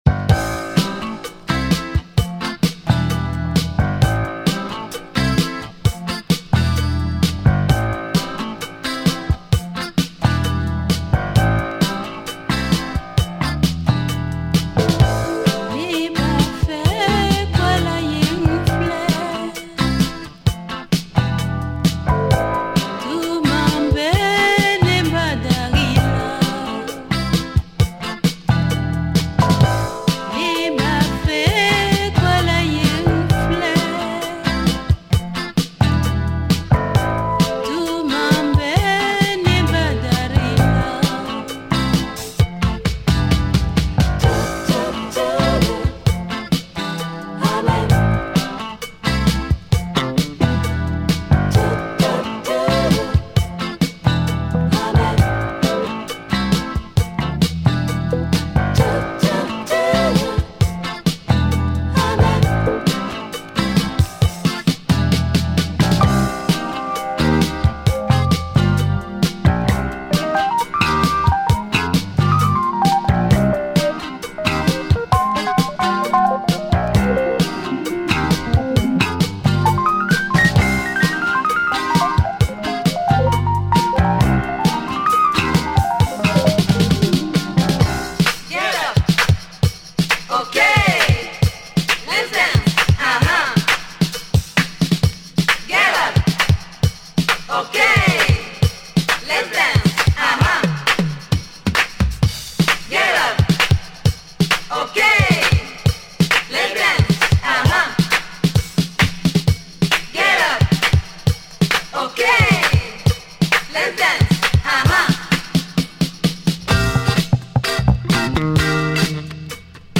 Lovely afro boogie with electric piano
Hot breaks on this one !
Vinyl has a couple of very light marks and plays great.